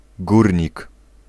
Ääntäminen
Synonyymit accessoire petit insignifiant annexe gueule noire Ääntäminen France: IPA: [mi.nœʁ] Haettu sana löytyi näillä lähdekielillä: ranska Käännös Ääninäyte Substantiivit 1. górnik {m} Suku: m .